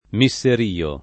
[ mi SS er & o ]